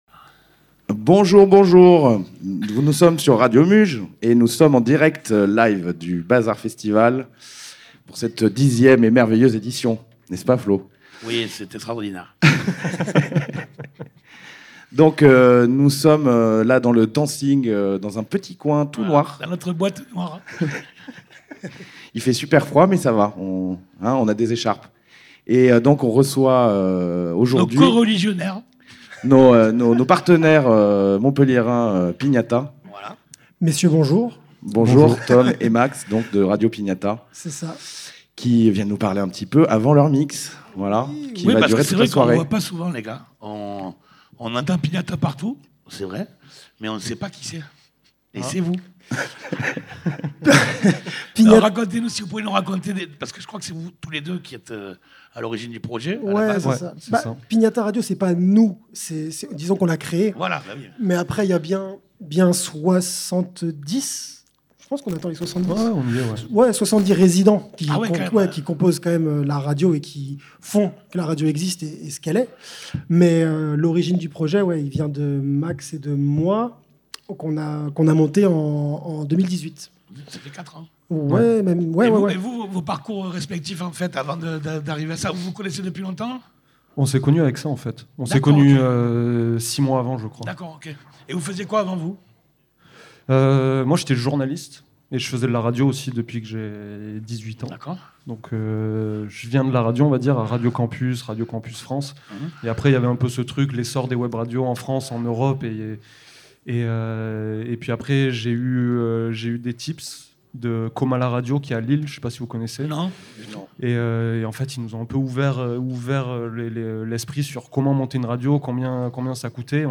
ITW des instigateurs de la célèbre radio montpelliéraine "Piñata", enregistrée lors du festival Bazr le samedi 10 Décembre juste avant leur DJ Set.